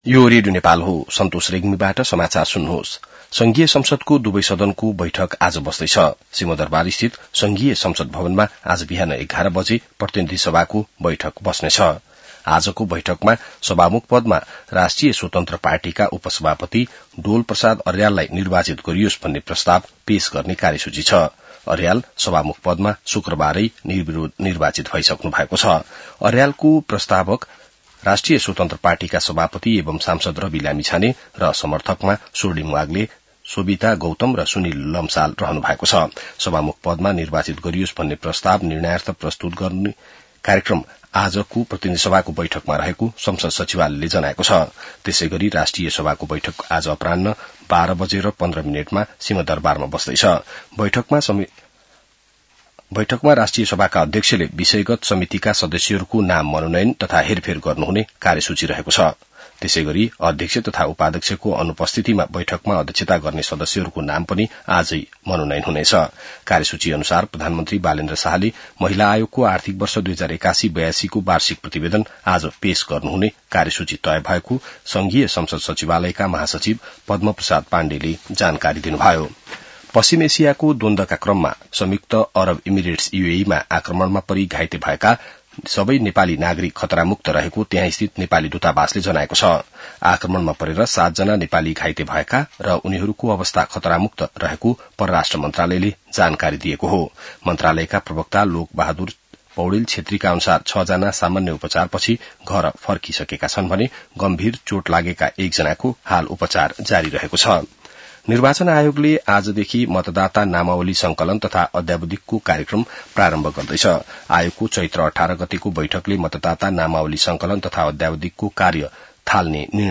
बिहान ६ बजेको नेपाली समाचार : २२ चैत , २०८२